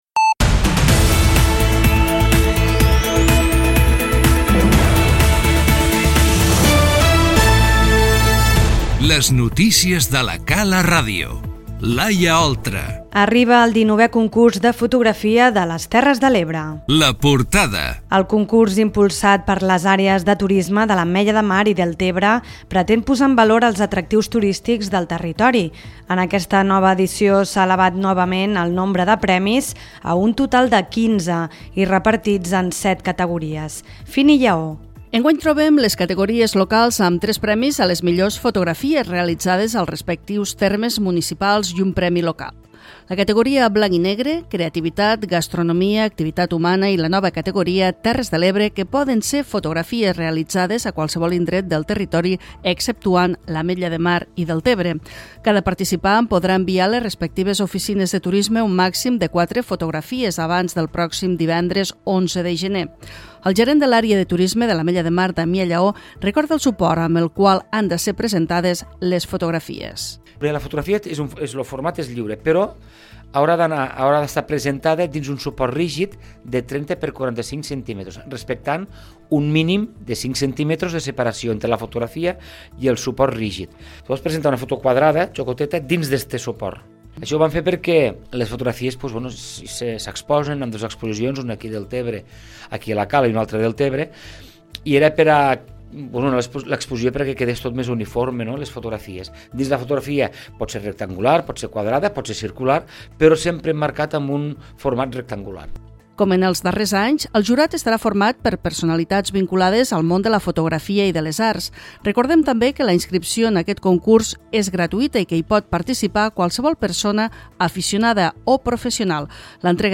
Les notícies 26/10/2018